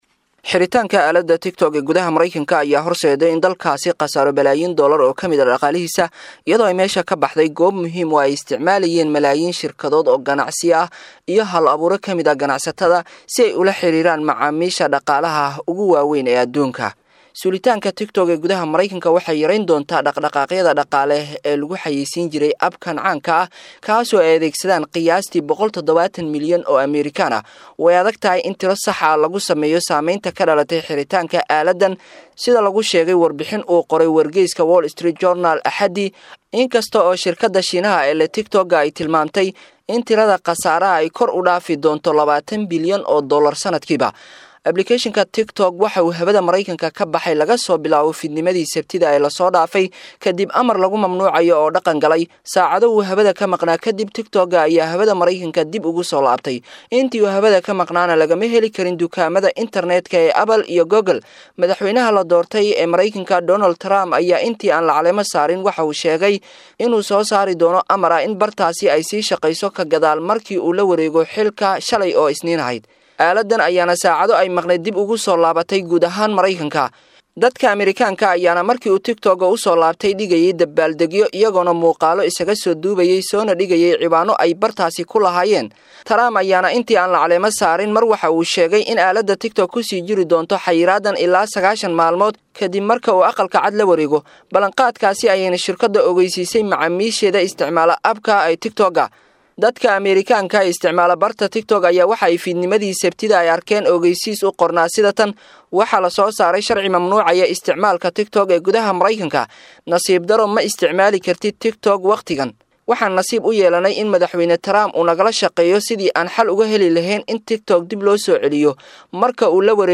Warbixin_Dhaqaale_Intee_la_Eg_ayey_Ku_Weysay_Dowladda_Mareykanka.mp3